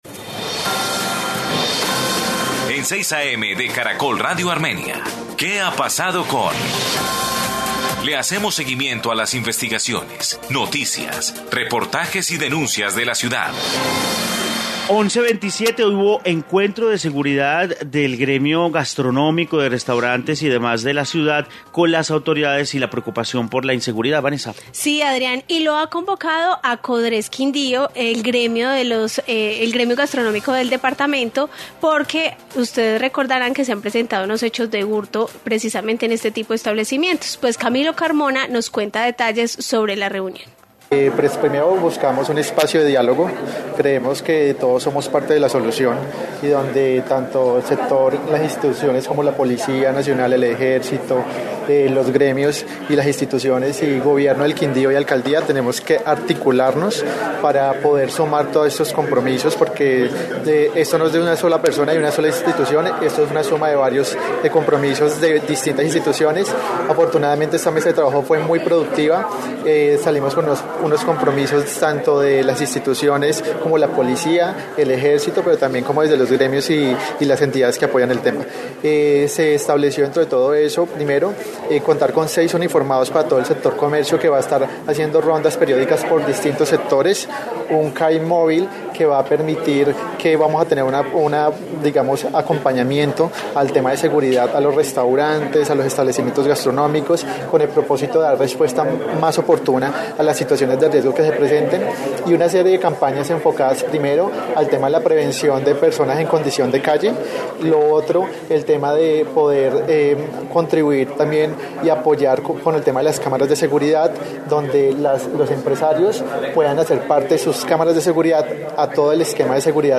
Informe mesa de seguridad gremios y autoridades